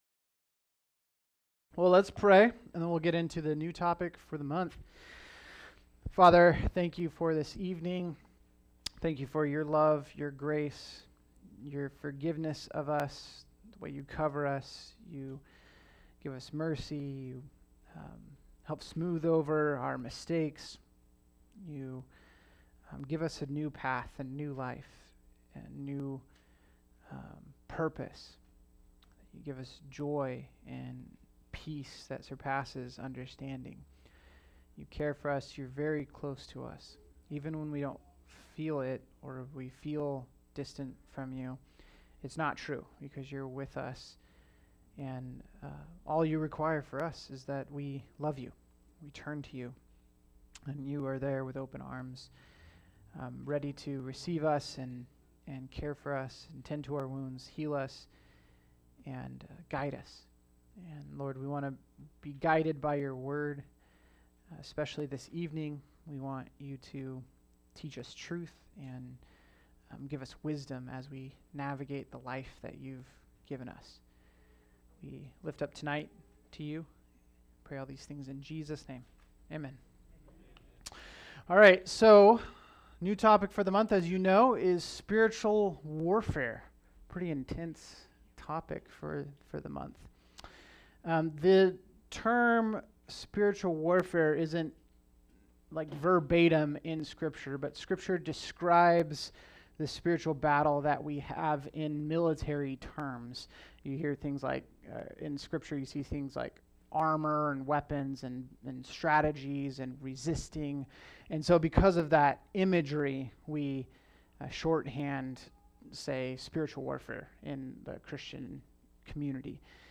All Sermons Intro to Spiritual Warfare March 5